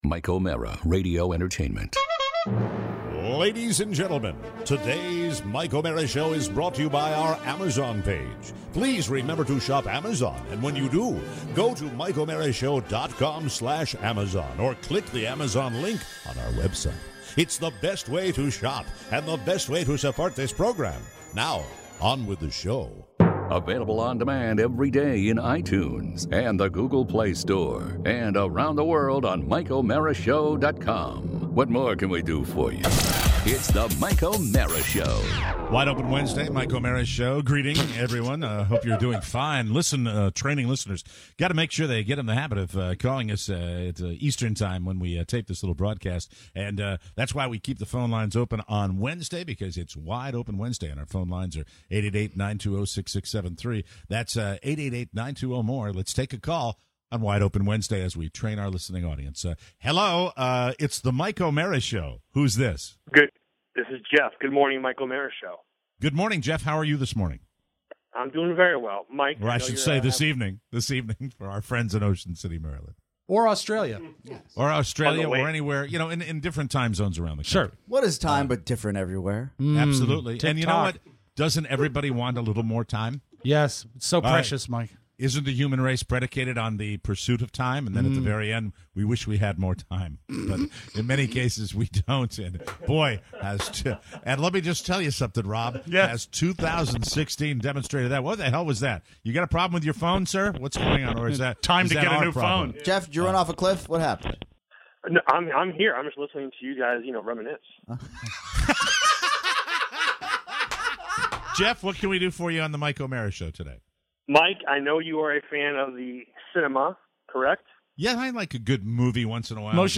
Your calls on Wide Open Wednesday! Plus… a little tropical depression, 21st birthday protocols… and the psychosis of us.